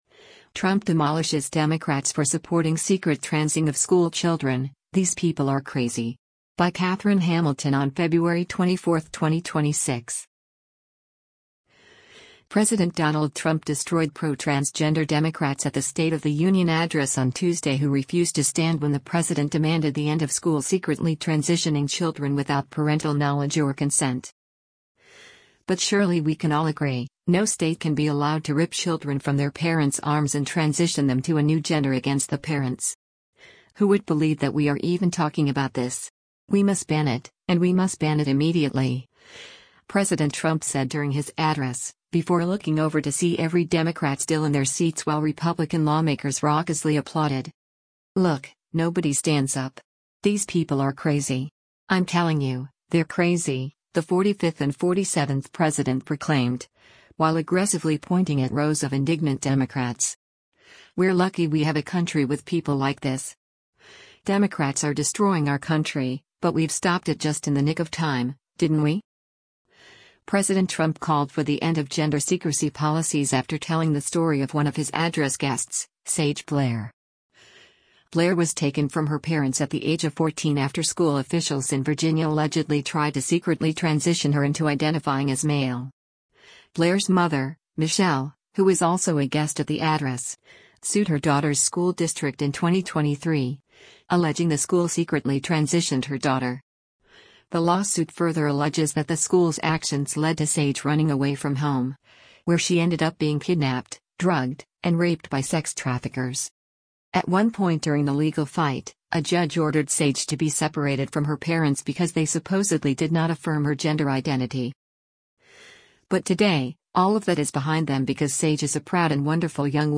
President Donald Trump destroyed pro-transgender Democrats at the State of the Union address on Tuesday who refused to stand when the president demanded the end of schools secretly transitioning children without parental knowledge or consent.